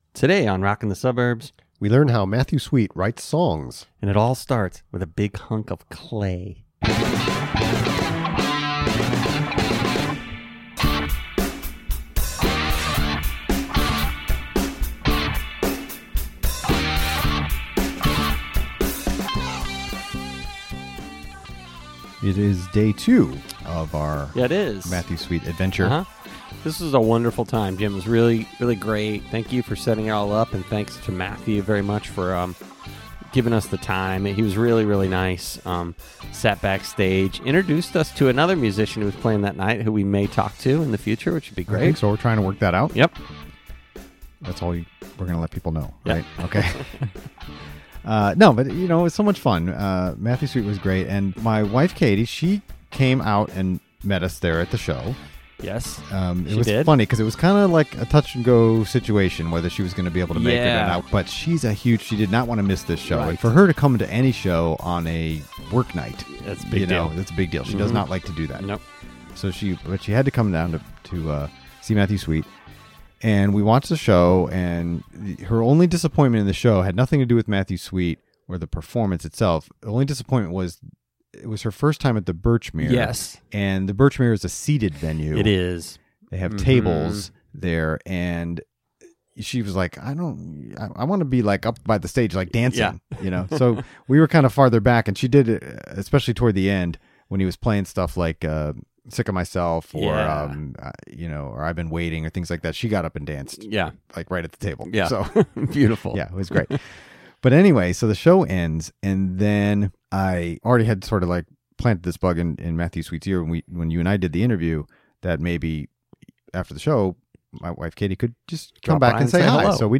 Interview: Matthew Sweet, Part 2